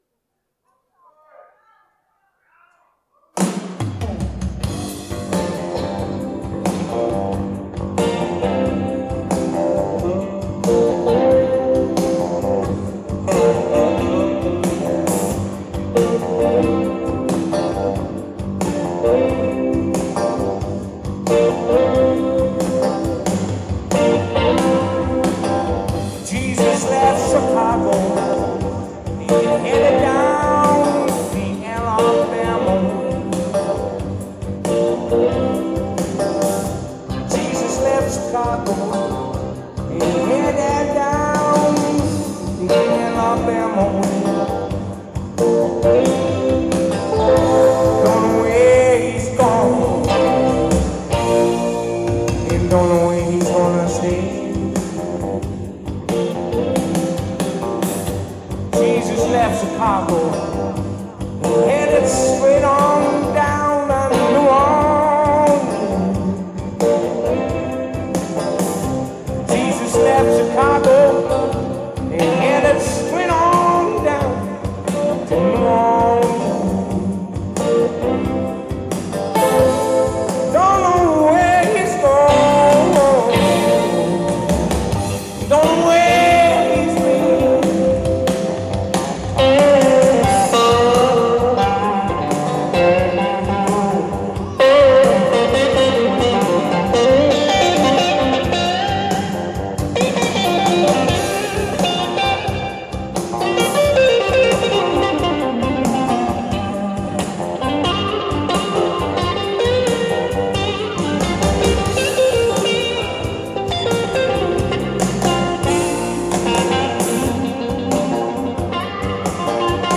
Ancienne Belgique, Brussels
keyboards